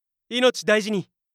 パロディ系ボイス素材　4
いのちだいじに_モノラル修正.mp3